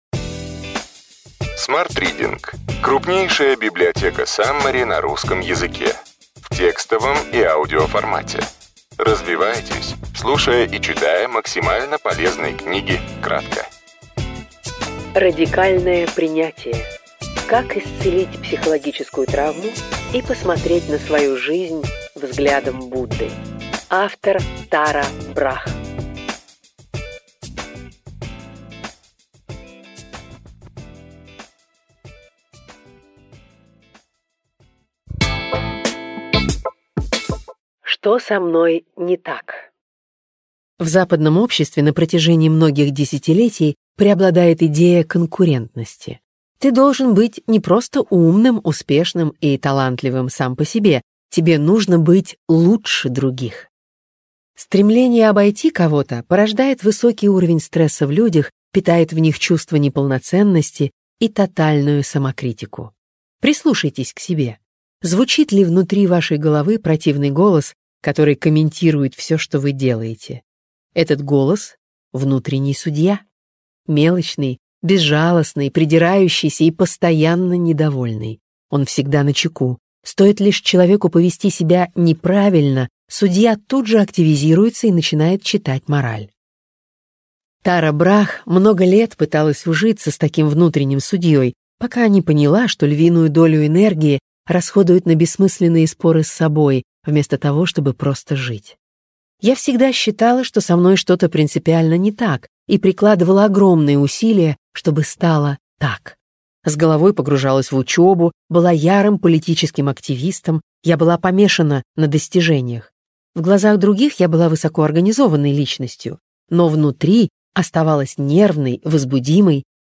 Аудиокнига Радикальное принятие. Как исцелить психологическую травму и посмотреть на свою жизнь взглядом Будды.